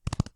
Horse Gallop 1.wav